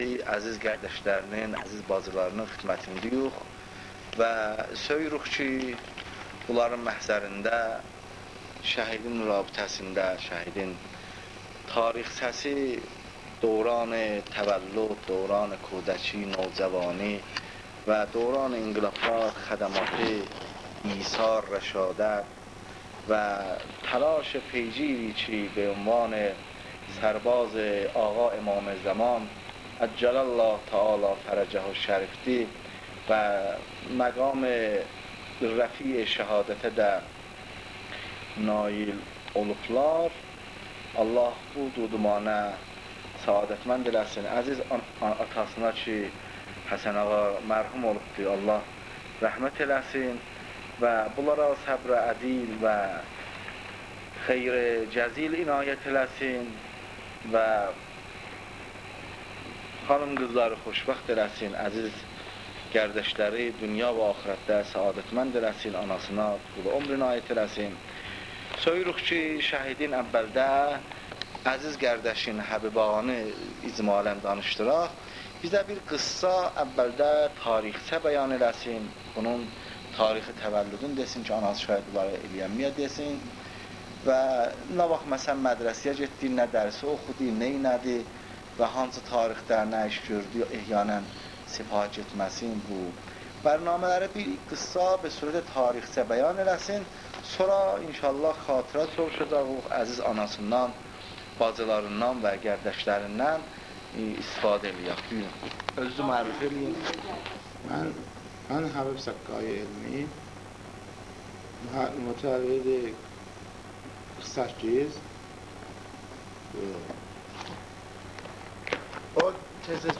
مصاحبه اختصاصی